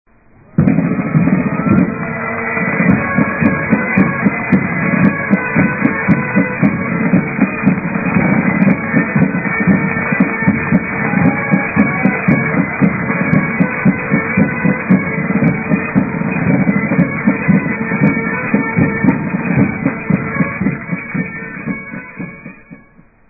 Sound Effects (Instructions: play)